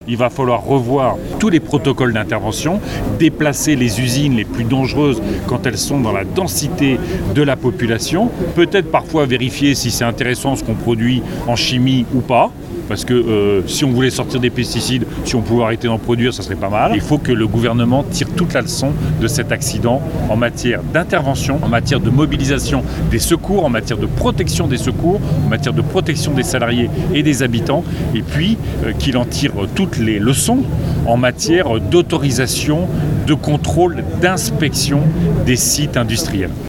Manifestation pour la vérité
Parmi les manifestants, le député européen EELV Yannick Jadot. Pour lui, cet accident industriel doit devenir un exemple, dont le gouvernement doit tirer toutes les conséquences: